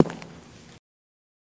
Singles|Wood
Footstep, Male On Hardwood, Loafer Right